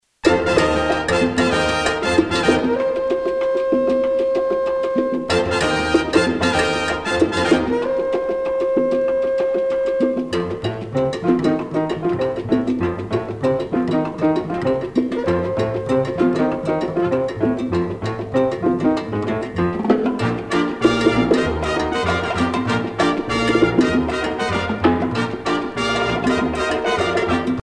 danse : mambo
Pièce musicale éditée